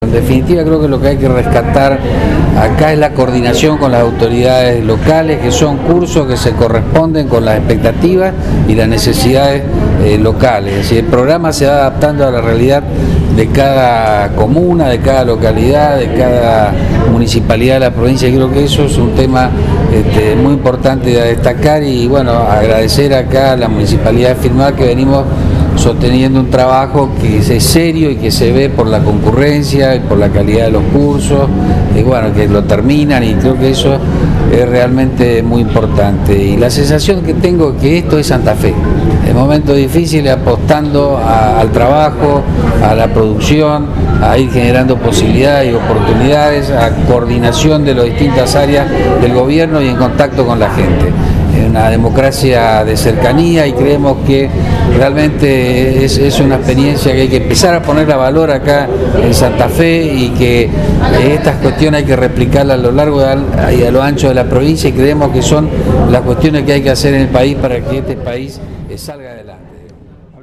El acto se llevó a cabo en el Hall Central Municipal y contó con la participación del director de Empleo y Formación Profesional del Ministerio de Trabajo Mariano Giunta, funcionarios provinciales y municipales, directivos y docentes de la Escuela Técnica Nº 281 y del ISECC, instituciones donde se llevaron a cabo las capacitaciones.